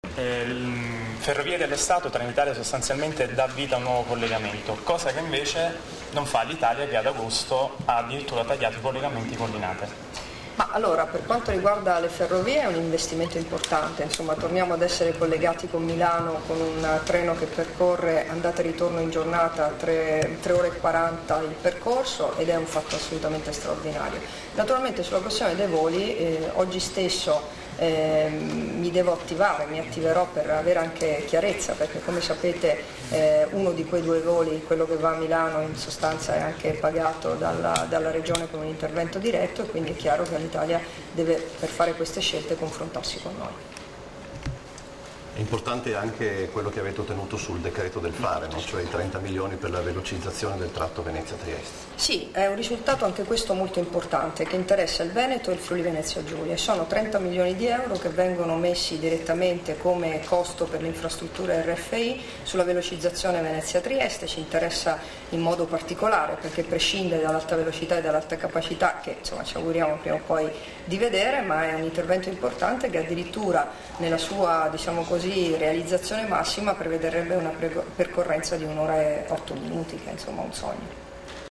Dichiarazioni di Debora Serracchiani (Formato MP3) rilasciate a margine della conferenza stampa sul nuovo collegamento ferroviario Trieste-Milano, a Trieste, il 6 agosto 2013 [1305KB]